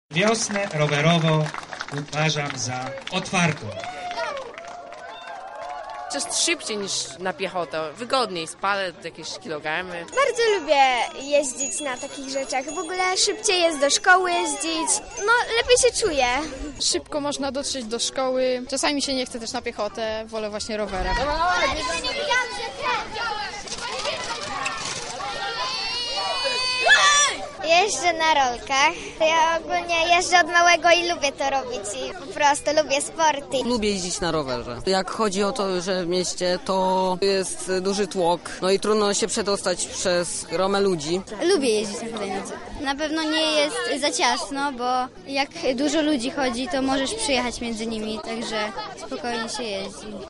Na miejscu była nasza reporterka: